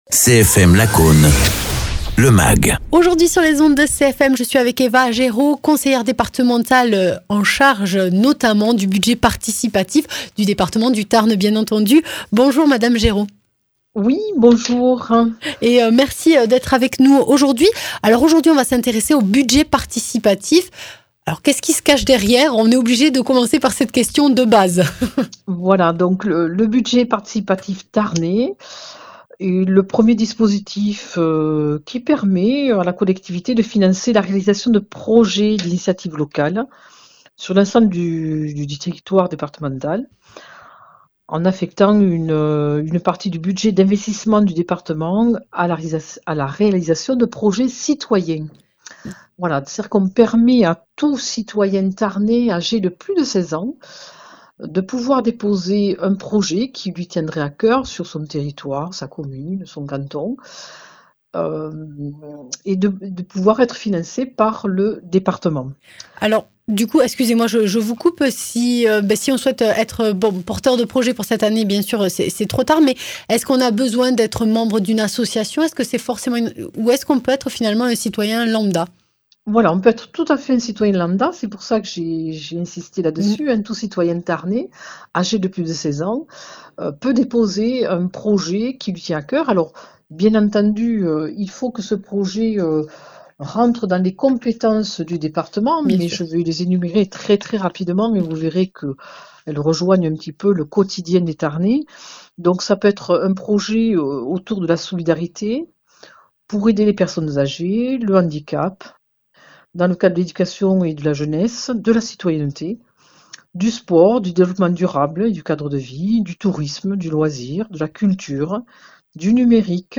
Interviews
Invité(s) : Eva Géraud, conseillère départementale du Tarn